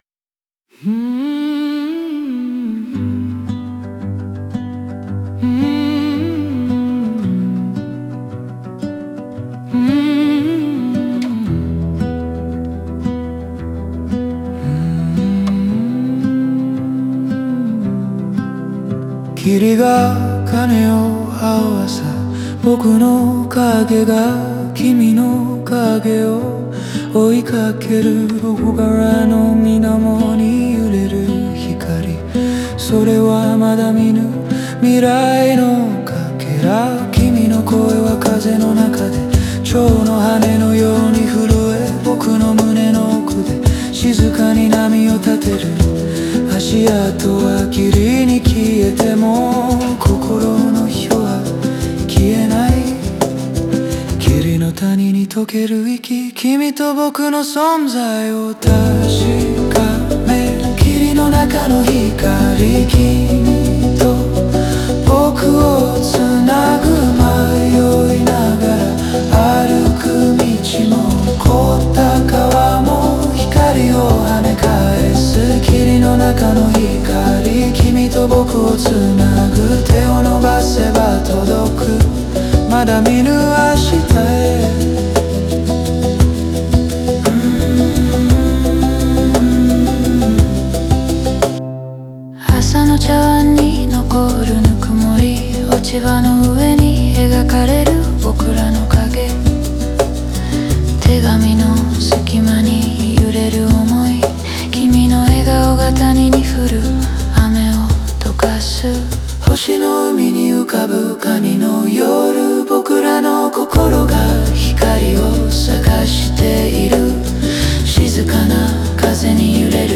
全体を通じて、自然と感情が融合し、静かで温かい余韻を残すフォークロック風の叙情詩として仕上げられています。